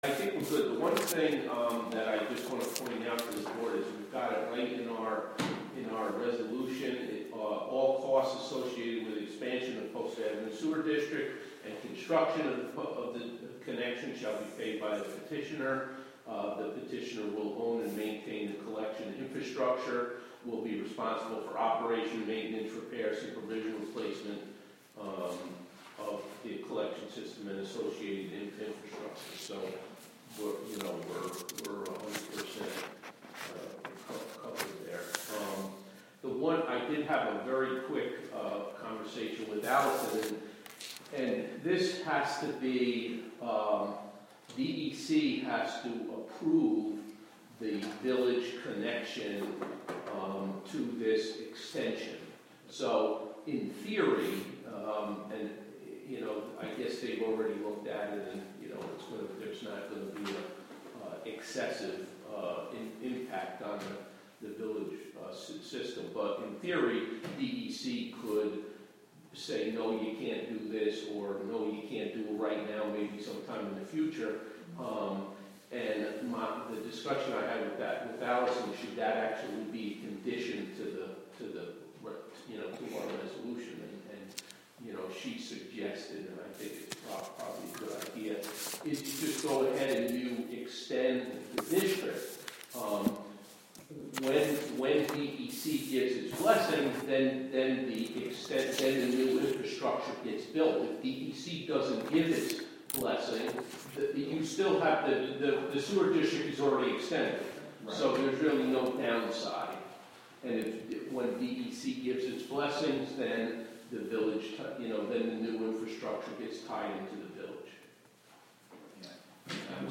Live from the Town of Catskill: April Town Board Meeting (Audio)